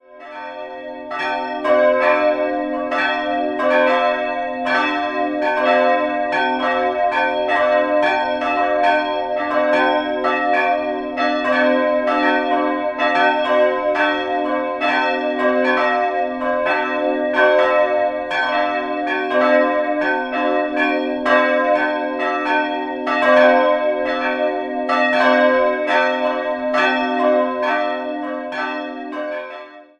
In den Jahren 1723/24 wurde die Filialkirche zum Heiligen Leonhard neu gebaut. Dabei verwendete man Teile des Turmes der Vorgängerkirche und erhöhte diesen. 3-stimmiges C-Moll-Geläute: c''-es''-g'' Die Glocken wurden im Jahr 1947 vom Bochumer Verein für Gussstahlfabrikation gegossen.